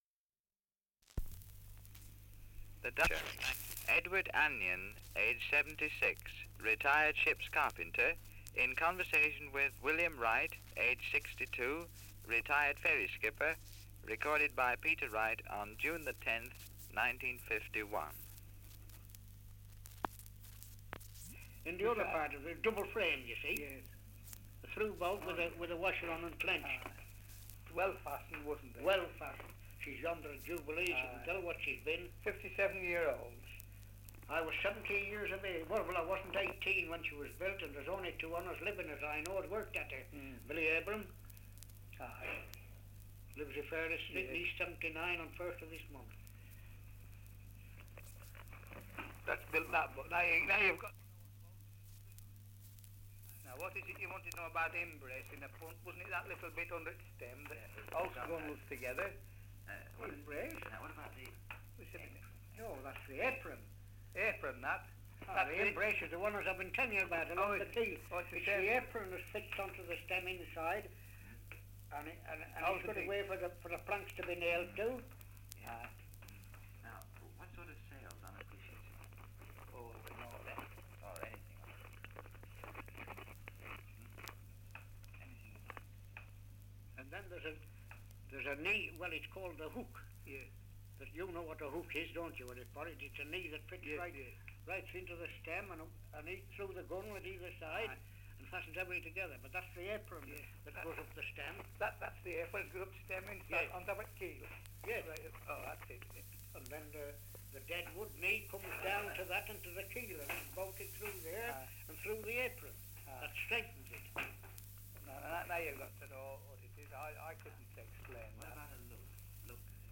Survey of English Dialects recording in Fleetwood, Lancashire
78 r.p.m., cellulose nitrate on aluminium